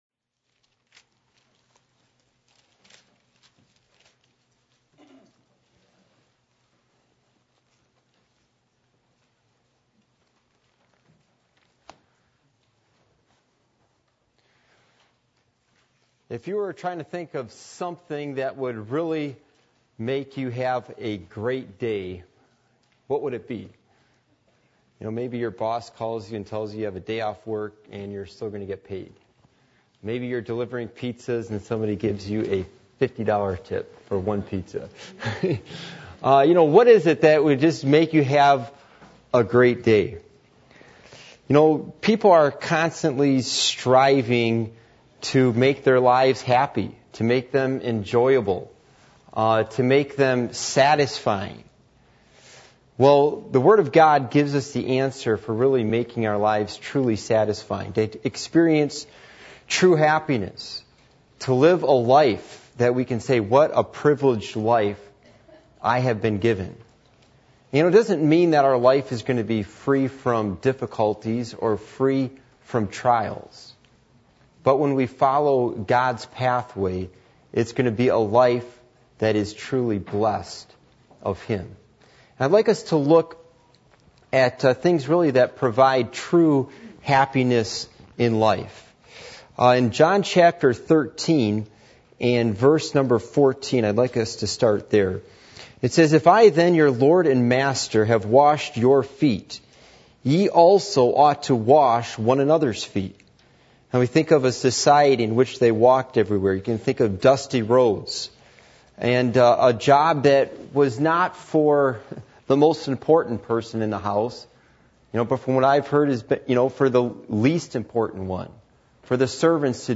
John 13:14-17 Service Type: Midweek Meeting %todo_render% « The Rarity of a Faithful Man Are You Going To Heaven When You Die?